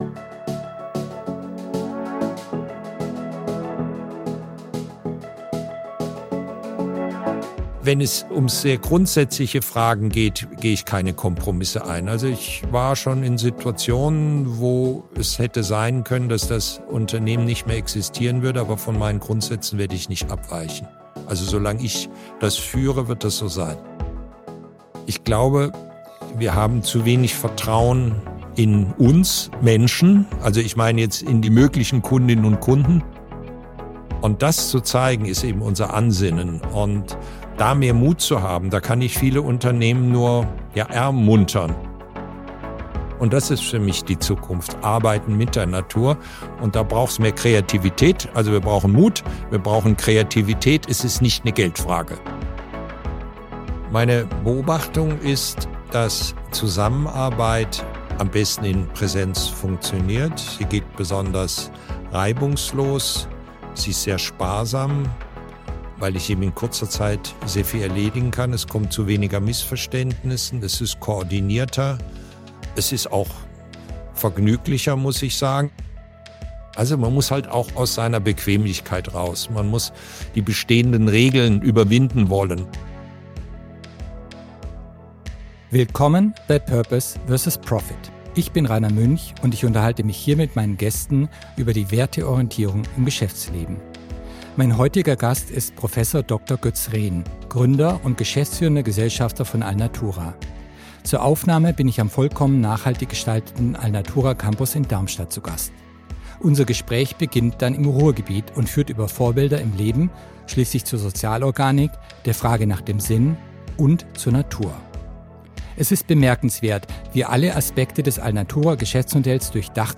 In der aktuellen Folge ist Professor Dr. Götz Rehn zu Gast, Gründer und geschäftsführender Gesellschafter von Alnatura. Das Gespräch beginnt im Ruhrgebiet und führt über Vorbilder im Leben schließlich zur Sozialorganik, der Frage nach dem Sinn und zur Natur.